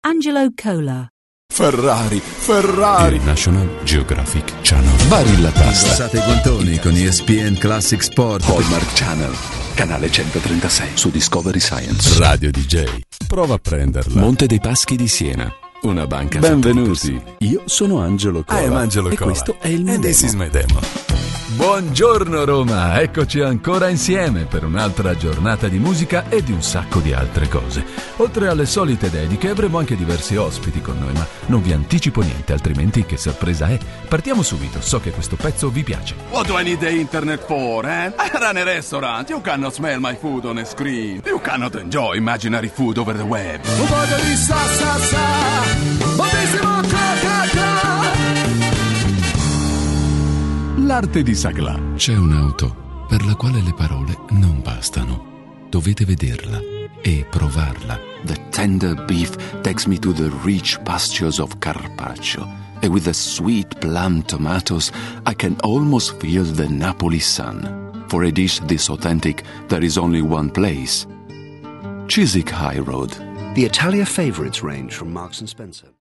Showreel 0:00 / 0:00